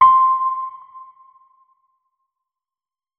electric_piano
notes-60.ogg